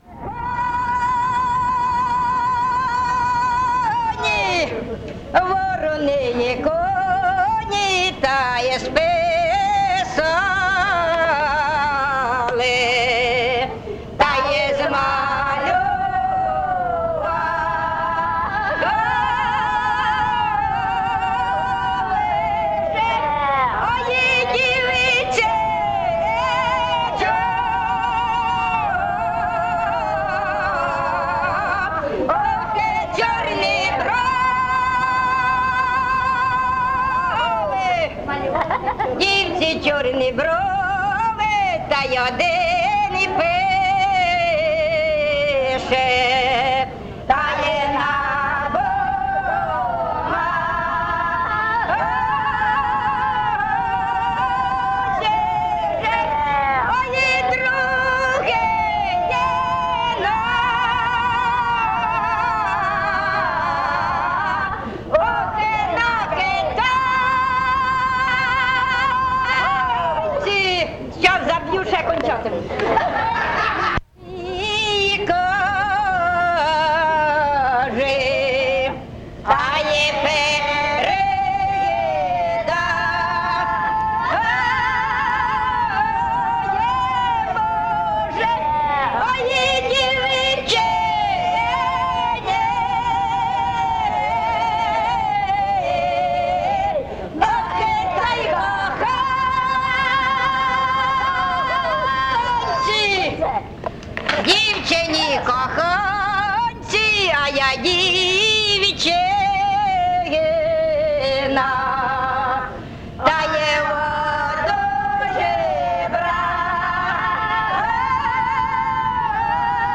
ЖанрПісні з особистого та родинного життя, Козацькі